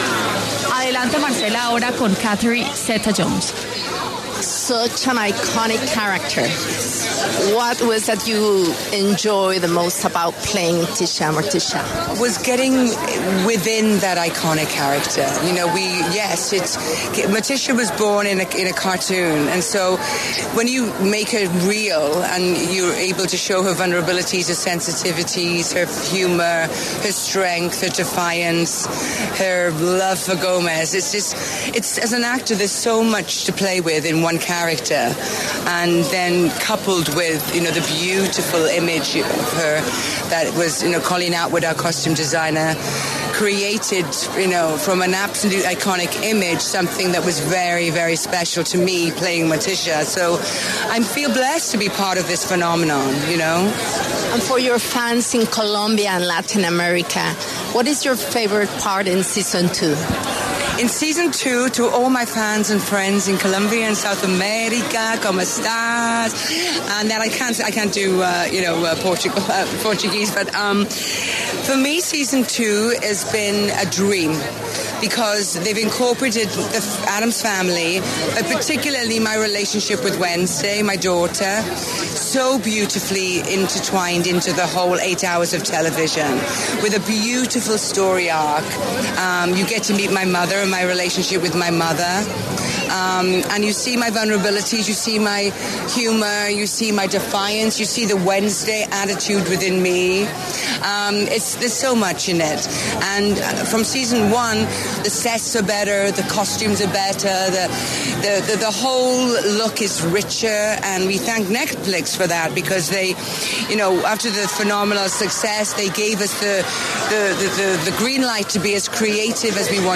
Catherine Zeta-Jones, quien interpreta a Morticia Addams en la serie de Netflix ‘Merlina’, conversó con La W, desde Londres, sobre la segunda temporada de la producción.